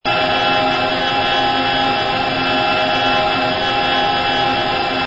engine_no_cruise_loop.wav